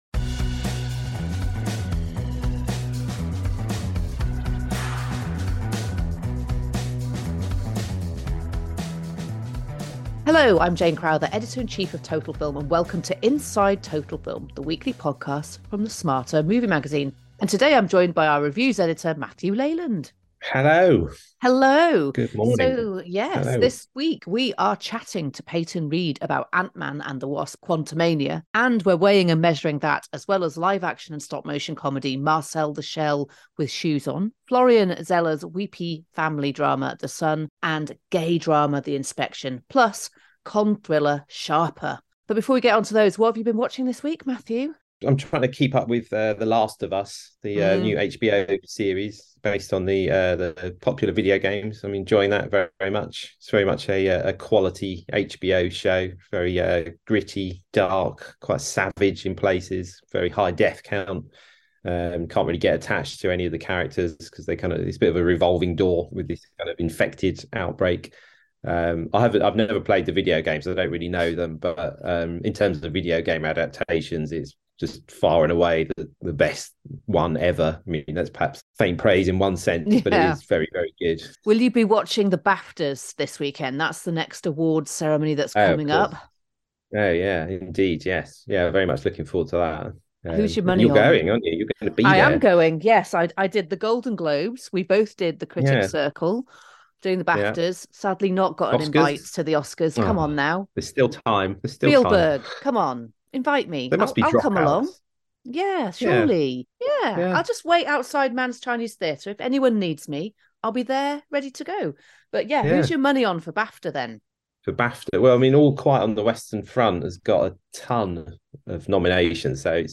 with an exclusive interview with the film’s director Peyton Reed